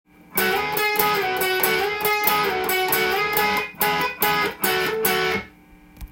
すべてのフレーズがDまたはD7で使えます。
④のフレーズは、和音系になっているので
Eの和音を弾いたあとにDの和音に着地させる